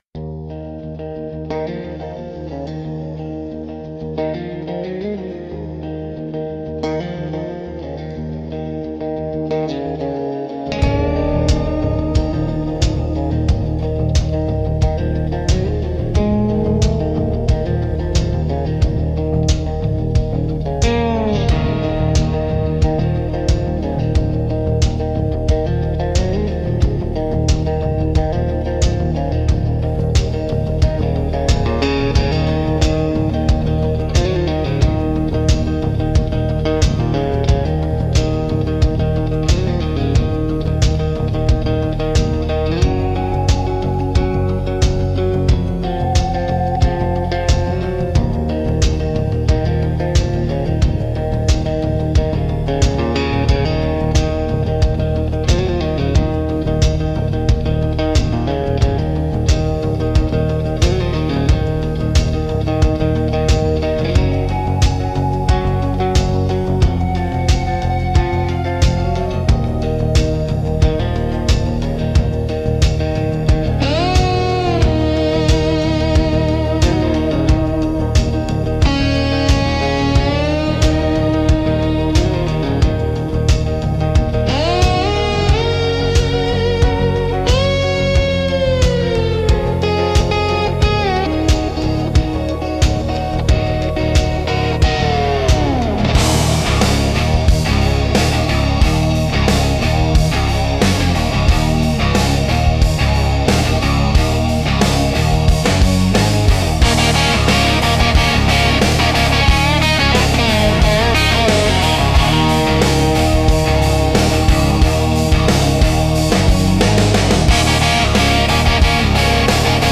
Instrumental - Wood Grain and Wire 4.29